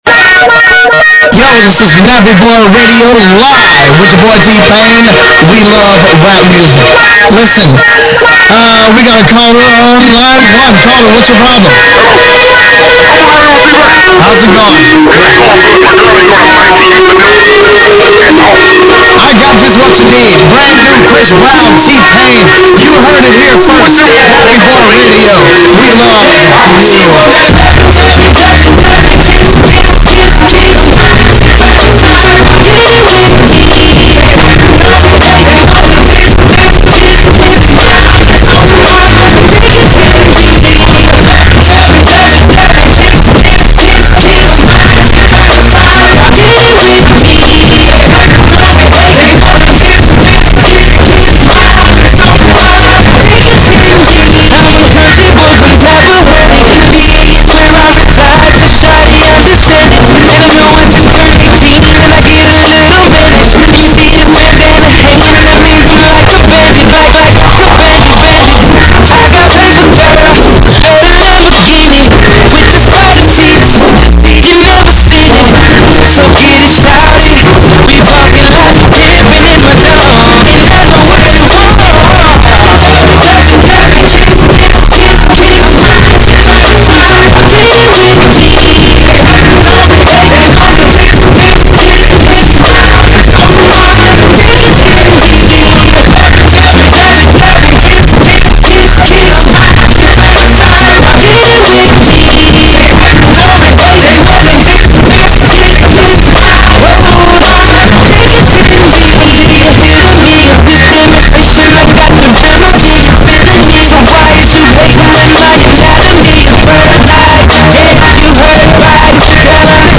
hip rap.